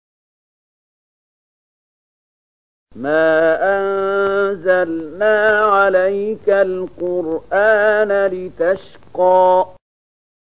020002 Surat Thaahaa ayat 2 dengan bacaan murattal ayat oleh Syaikh Mahmud Khalilil Hushariy: